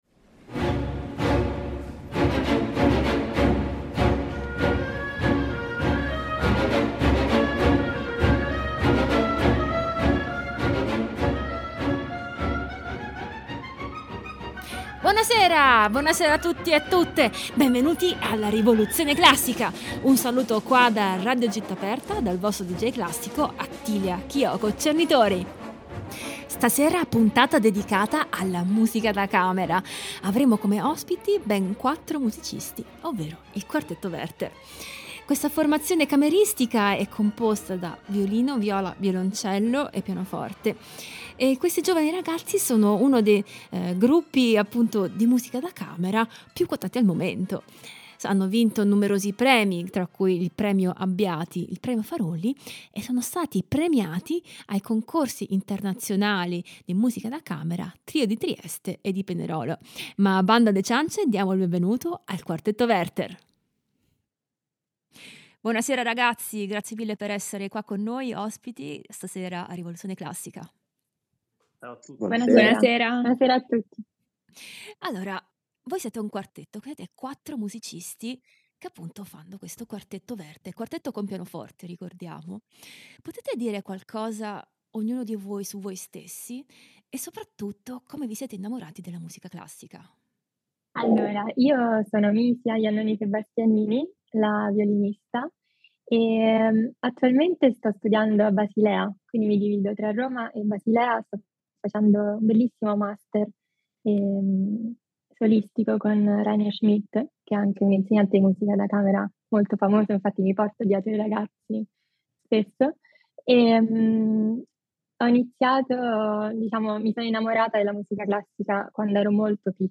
Ospite di questa puntata il quartetto Werther